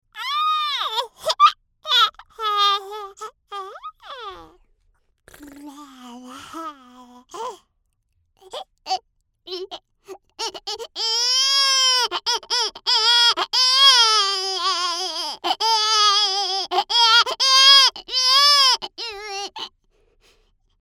Specialty - Baby